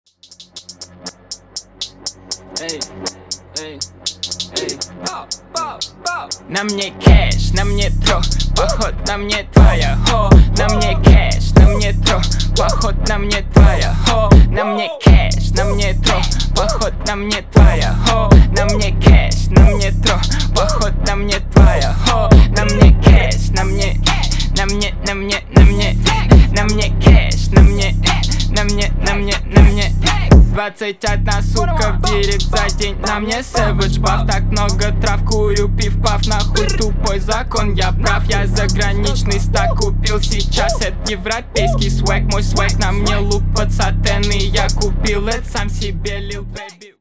• Качество: 320, Stereo
громкие
русский рэп
мощные басы
грубые